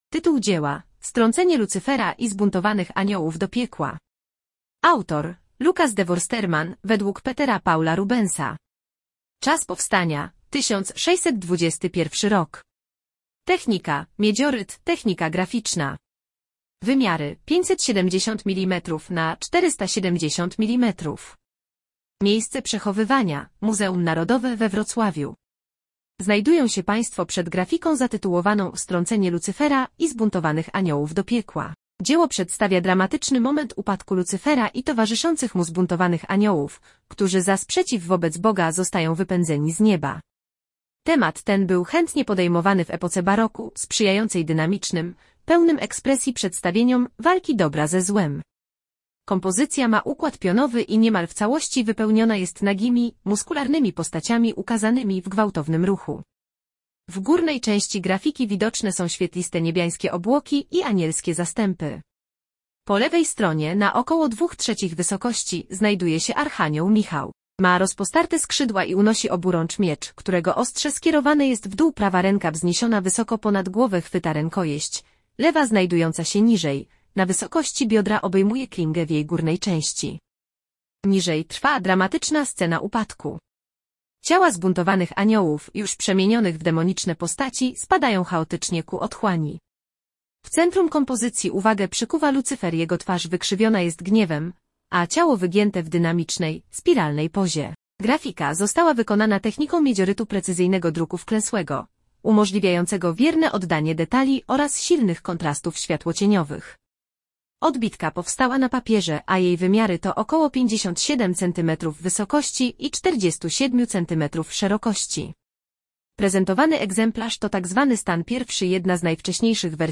MNWr_audiodeskr_Stracenie_Lucyfera_i_zbuntowanych_aniolow_do_piekla.mp3